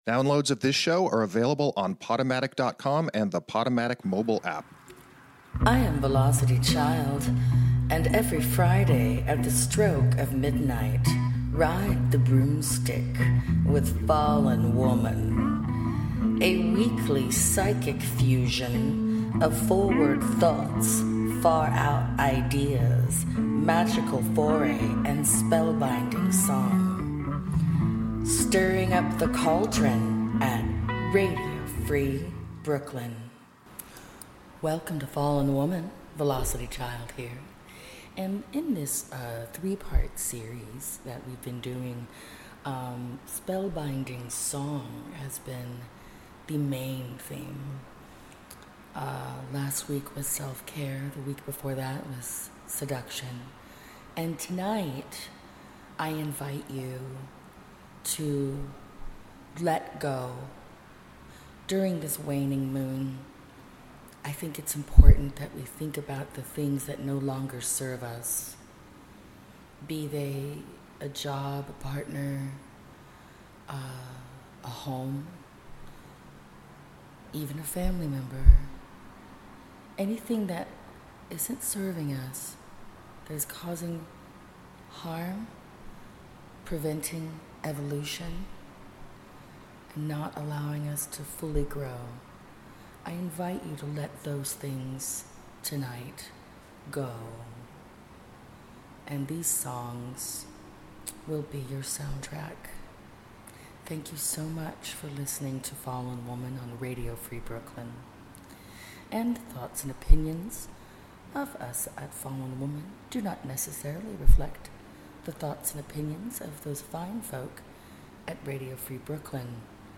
Headliner Embed Embed code See more options Share Facebook X Subscribe Featuring a special musical edition with the intention of providing a background to your banishing spells honoring this weekend's waning moon✨ Burn a white candle in a bowl of sea salted water while meditating on your intent.